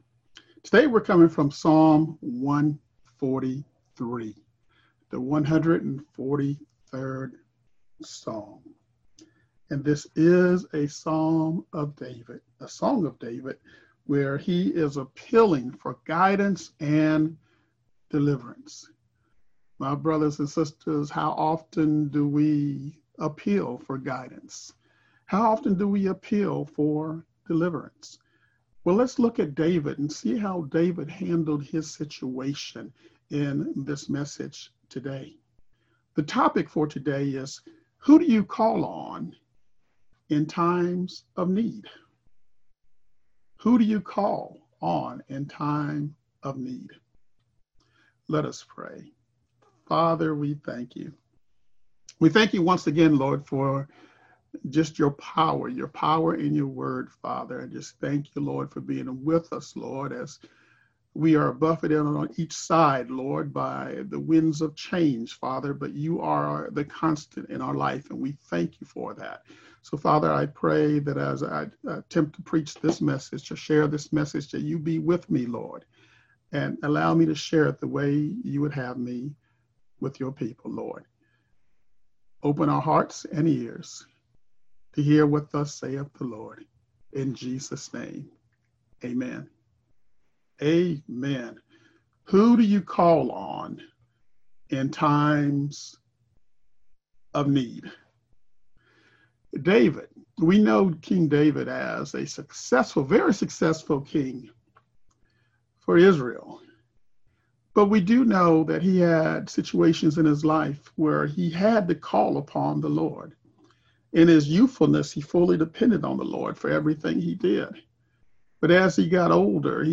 Virtual Service for Sunday, July 12, 2020 Message Topic: A Servant's Appeal Scripture: Psalm 143